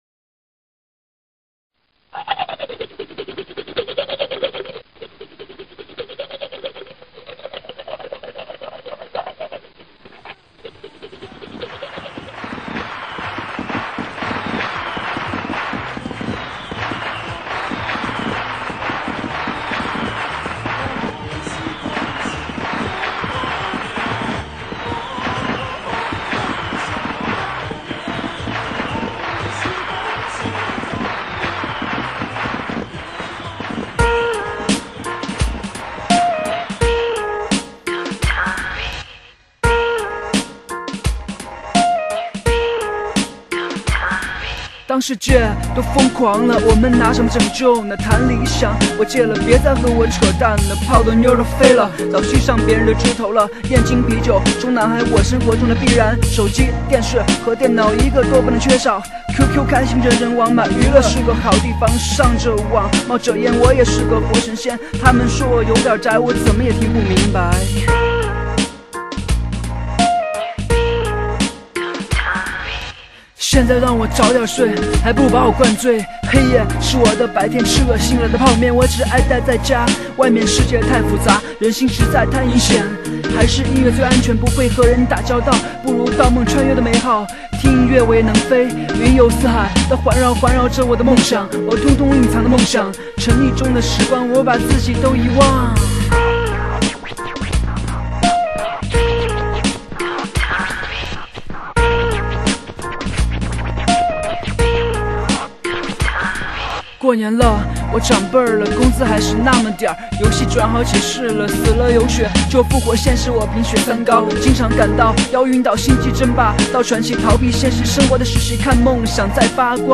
风格：流行/Pop